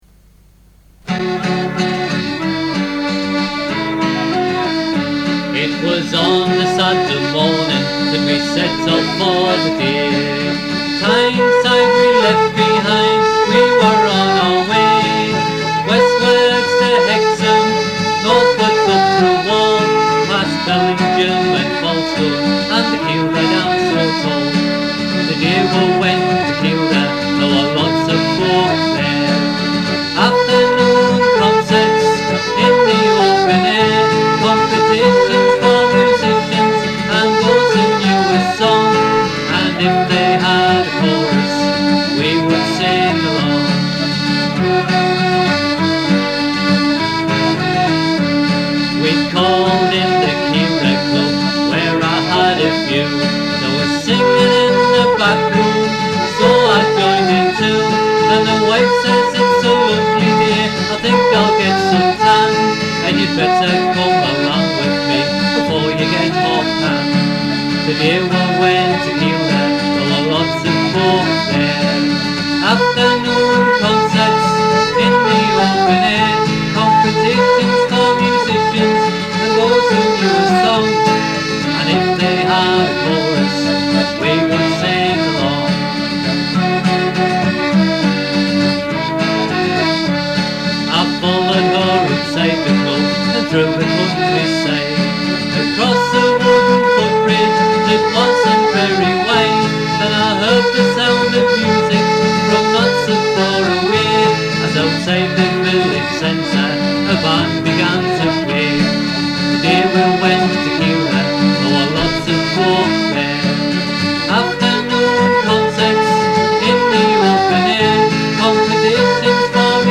Vocals & Guitar
Recorder
Flute
Piano Accordion.